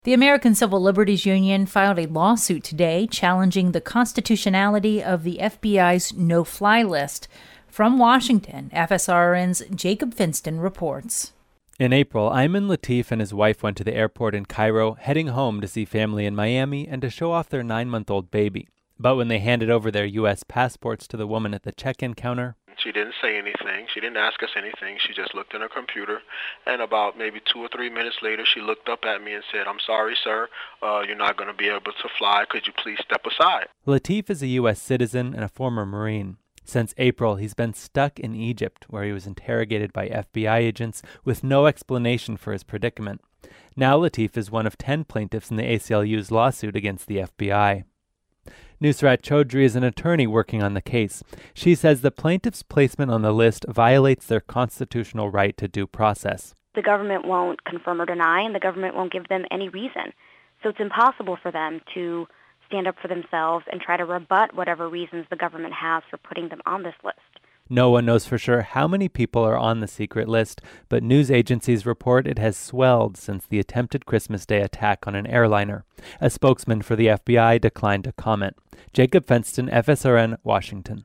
The American Civil Liberties Union filed a lawsuit today challenging the constitutionality of the FBI’s no-fly list. From Washington